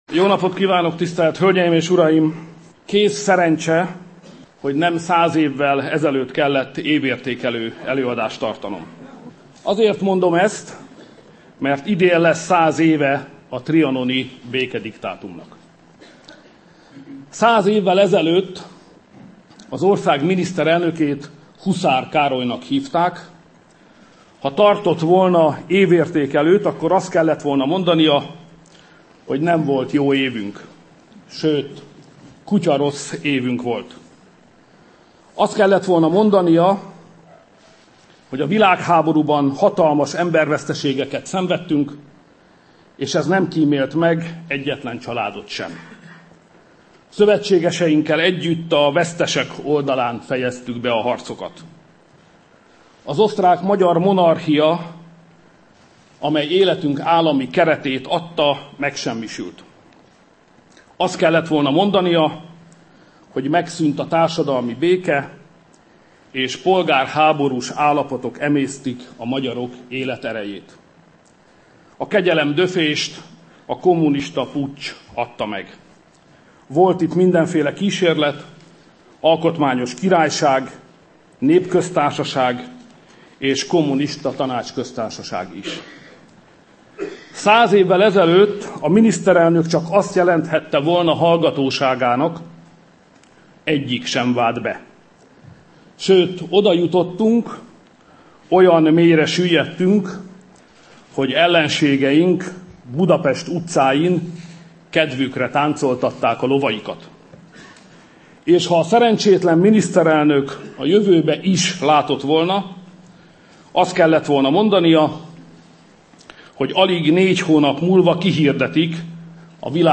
Nézze és hallgassa meg újra Orbán Viktor évértékelő beszédét
A Várkert Bazárban tartotta 22. évértékelő beszédét Orbán Viktor. A kormányfő a klímavédelmi akciótervről, a nemzeti konzultációról és a családvédelem terén szükséges intézkedésekről is beszélt.